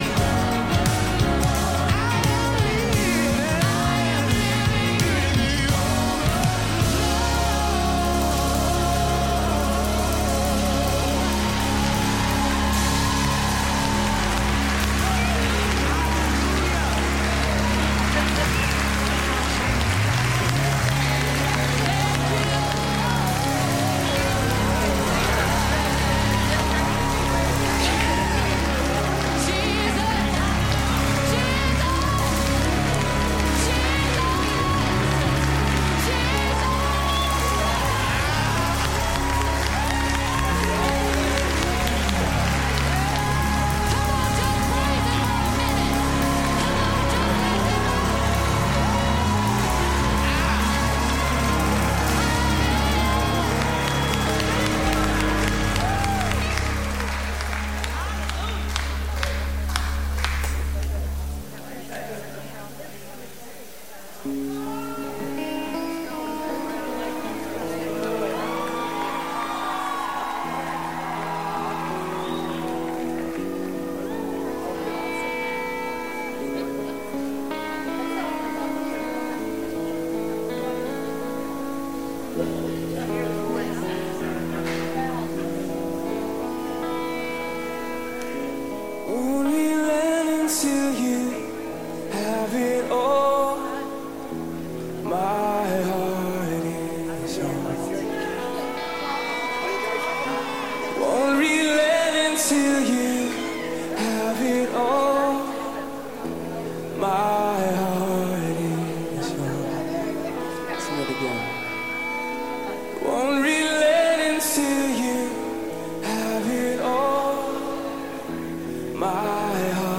The GO Sermon Series